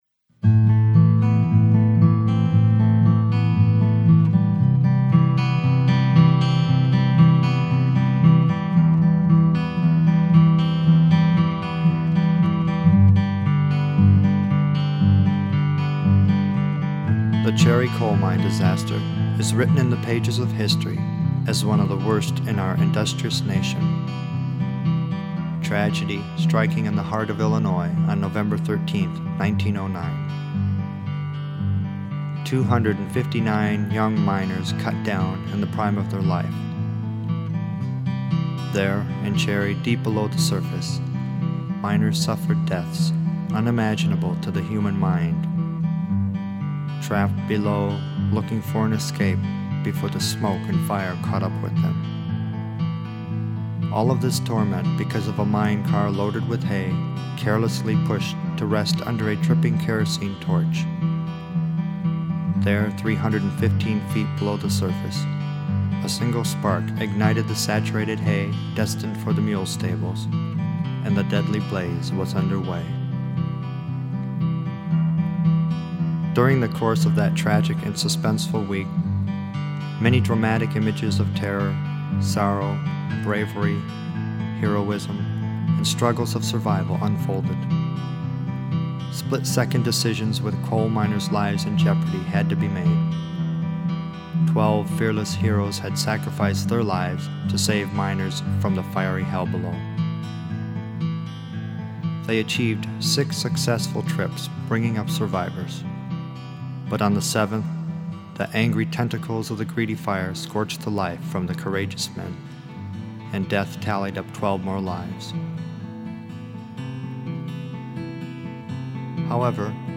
Cherry Mine Disaster Narration